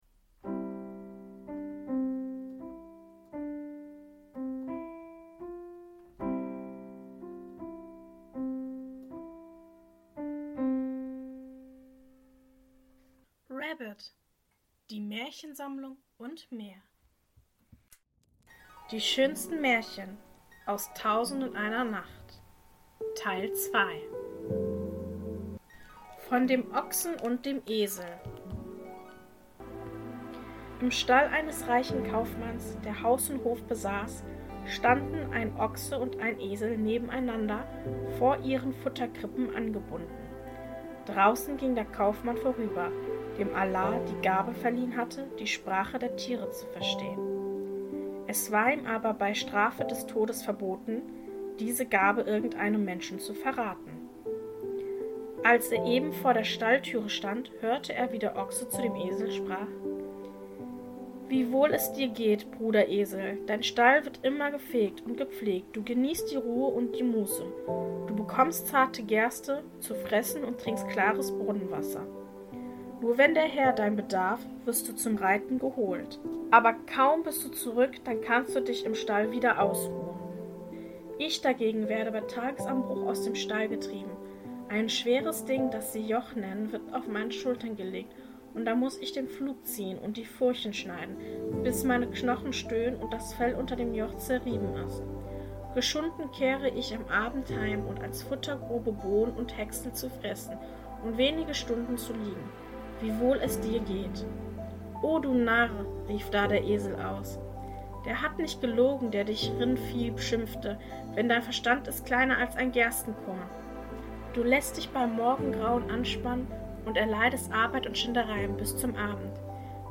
In der heutigen Folge lese ich Folgendes vor: 1.